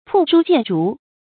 曝書見竹 注音： ㄆㄨˋ ㄕㄨ ㄐㄧㄢˋ ㄓㄨˊ 讀音讀法： 意思解釋： 謂睹物思人。